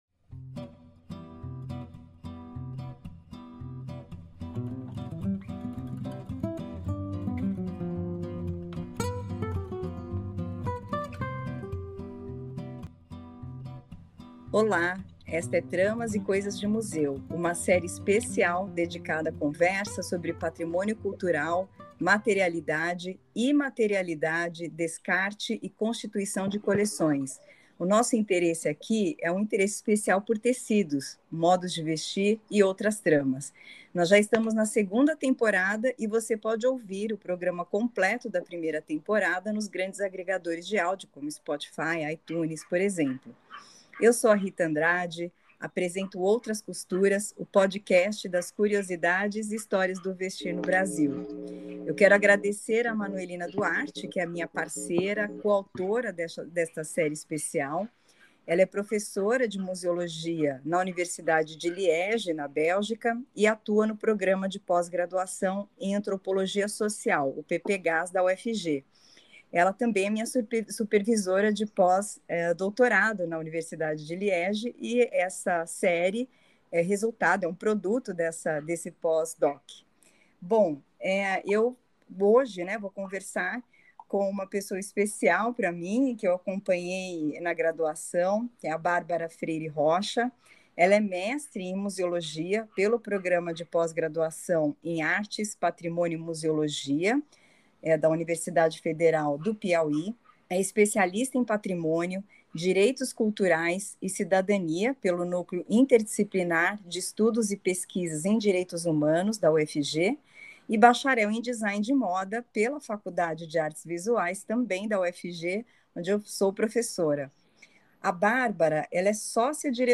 Send to RIS BibTex APA Chicago Permalink X Linkedin copy to clipboard copied Details Keywords : Heritage; Dress; Costume; Museum; Collections; Funding Abstract : [en] This series consists of a series of conversations about cultural heritage, materiality, immateriality, disposal and constitution of collections in museums and outside of them. The idea is to reflect on controversial and current issues related to Brazilian clothing and identities related to public goods considered as cultural heritage.